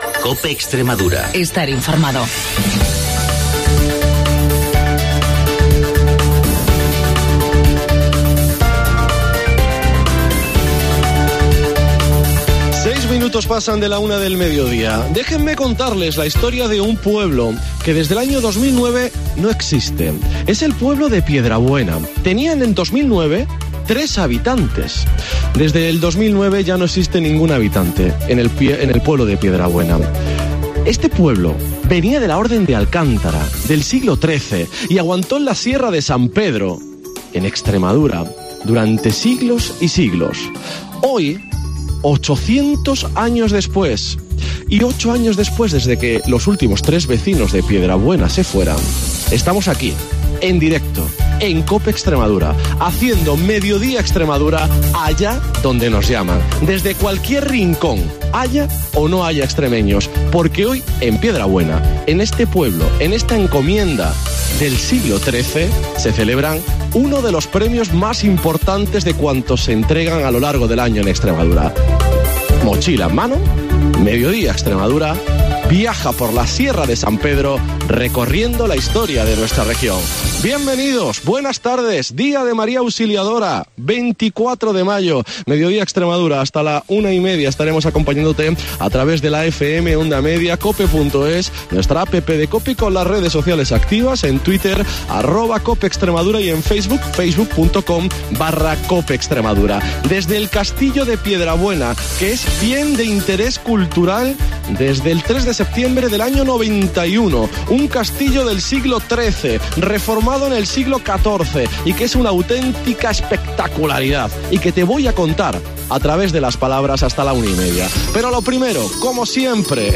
En el programa de hoy nos hemos desplazado en directo al castillo de Piedrabuena en la Sierra de San Pedro donde Caja Rural de Extremadura ha entregado sus PREMIOS ESPIGA al mejor vino y corcho.